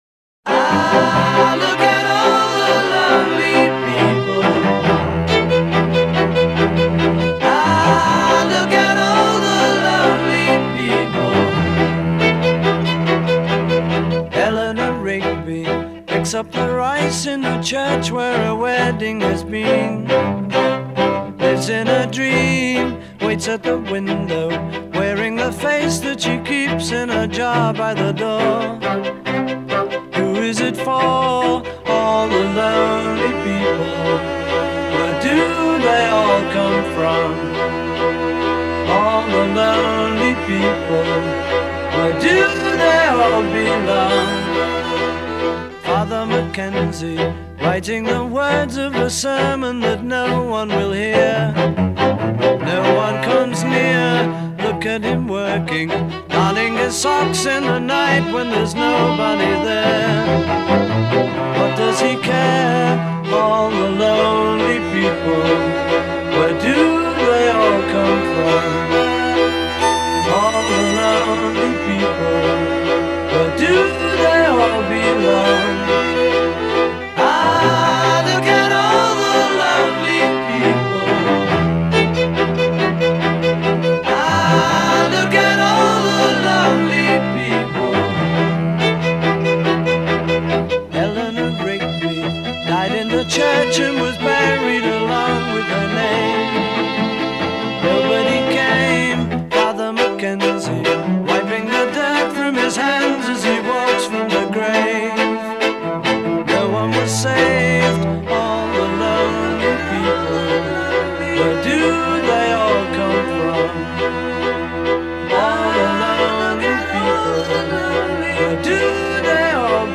electric guitar-rock sound